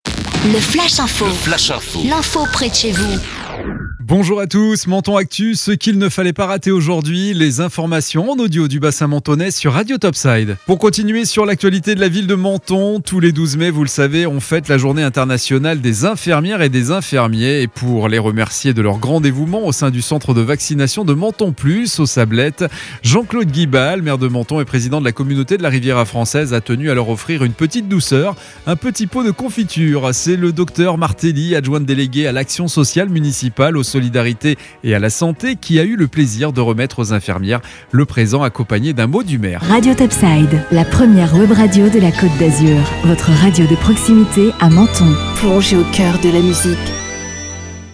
Menton Actu - Le flash info du mercredi 12 mai 2021